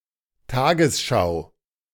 Tagesschau (pronounced [ˈtaːɡəsˌʃaʊ̯]